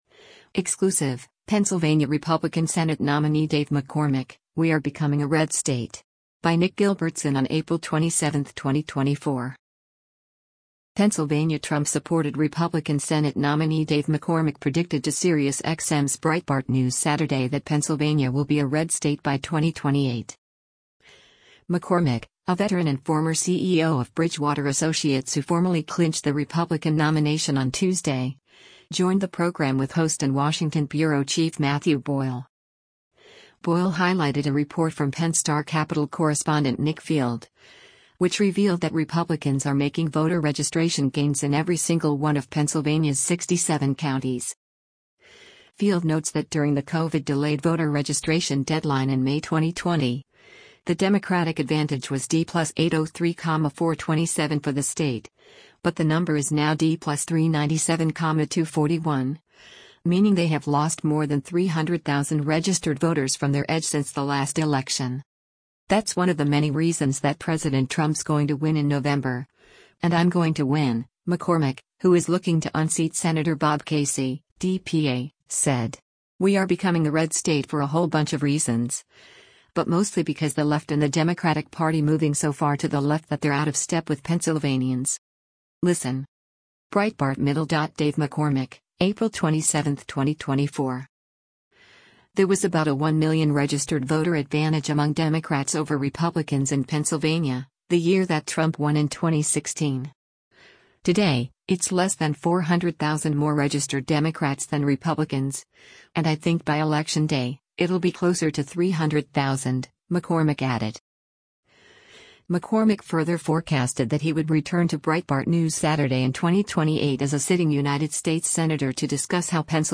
Pennsylvania Trump-supported Republican Senate nominee Dave McCormick predicted to Sirius XM’s Breitbart News Saturday that Pennsylvania will be a red state by 2028.